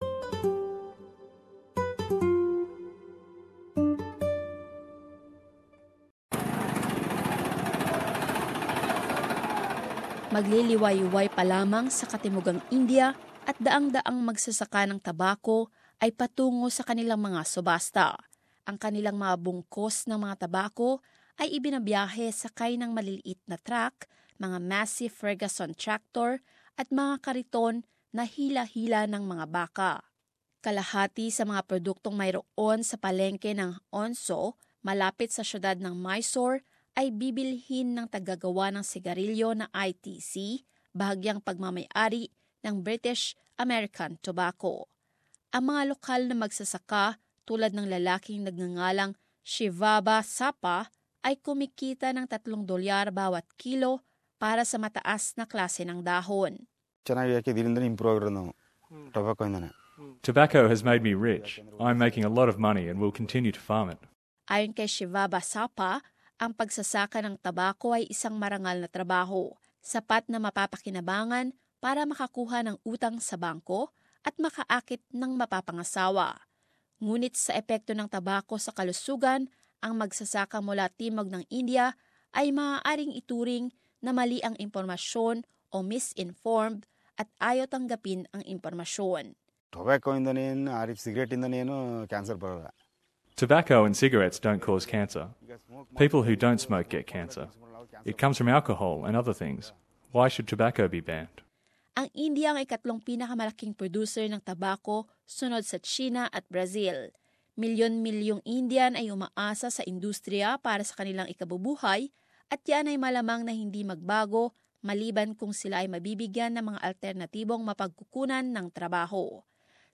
SBS travelled to the town of Hunsur, in the southern Indian state of Karnataka, to see how tobacco farmers feel about moves to curb their industry.